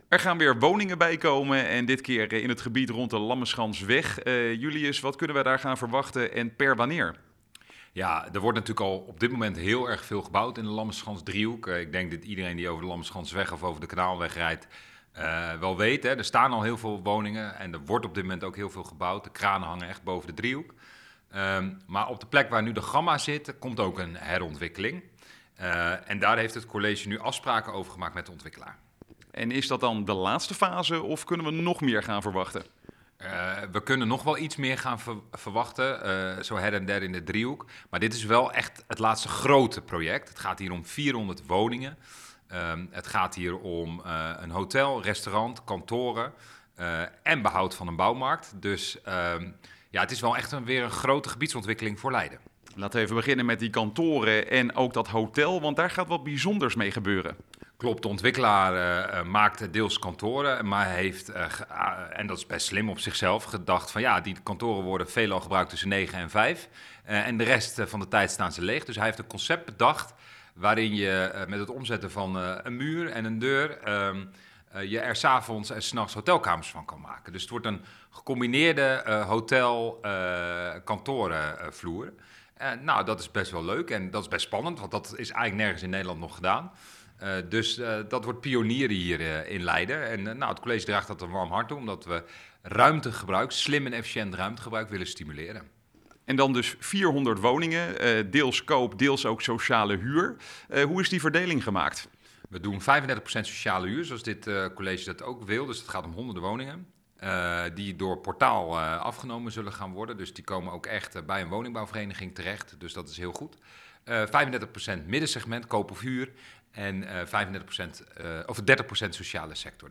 in gesprek met wethouder Julius Terpstra